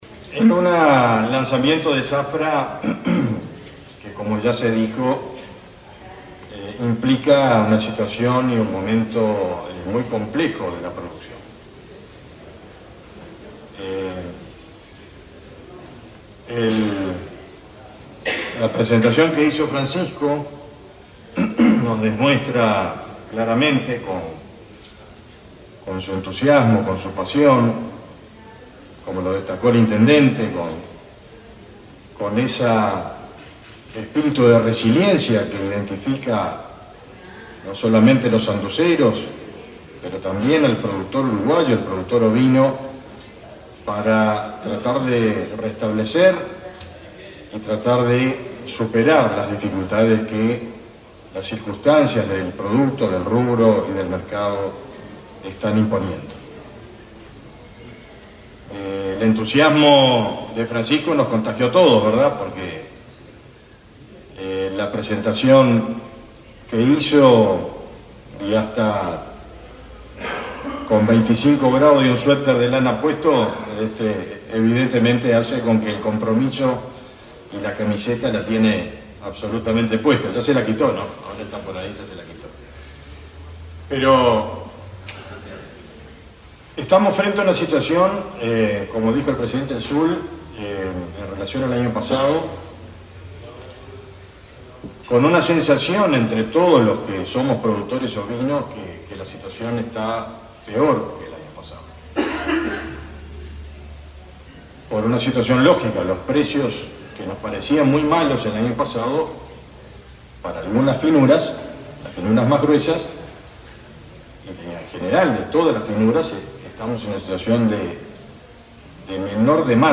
Palabras del ministro de Ganadería, Fernando Mattos
El ministro de Ganadería, Fernando Mattos, participó, este viernes 2 en Paysandú, en el lanzamiento de la zafra ovina.